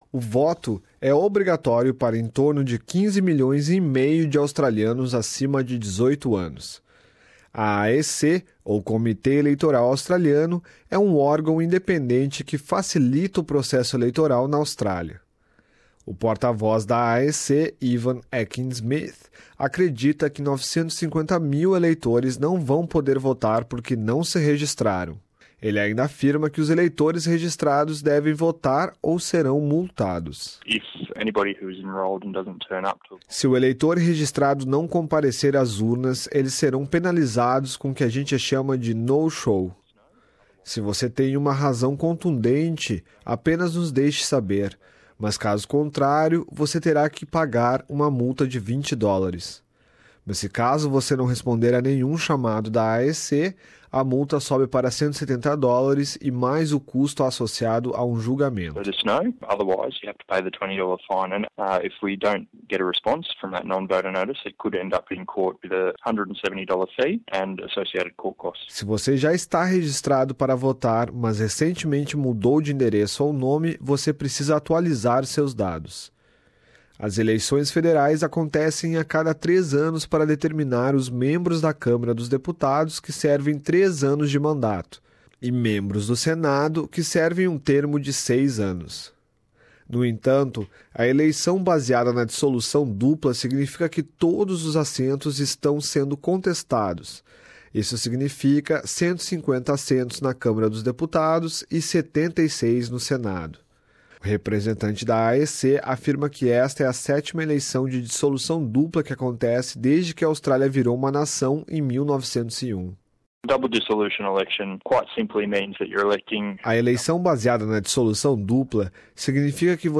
Nesta reportagem um guia de como funciona o processo eleitoral na Australia, e como se preparar para votar.